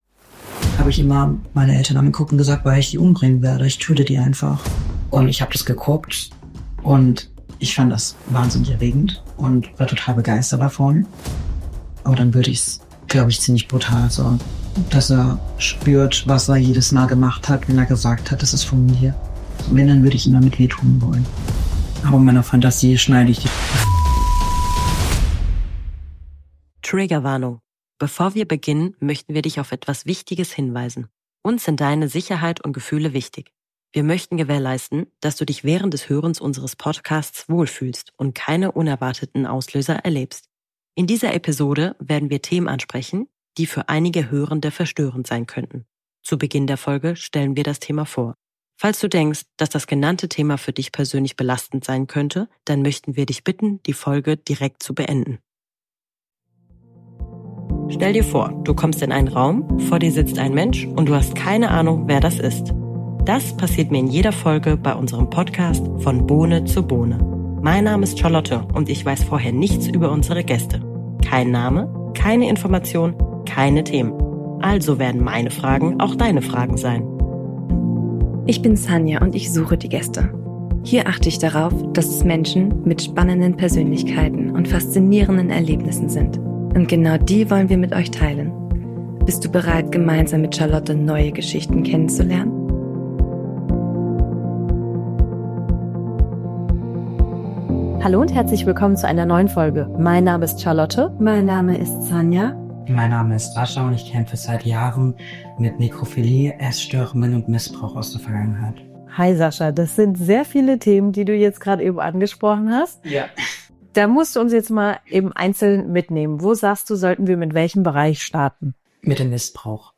In einem offenen und schonungslosen Gespräch